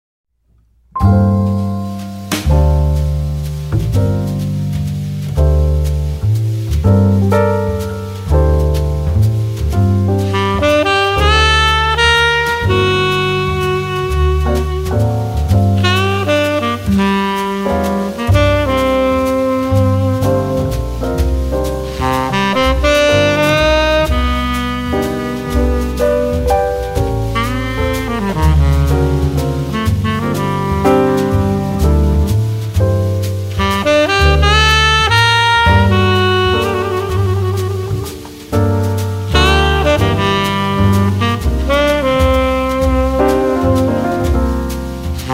Compilation Jazz Album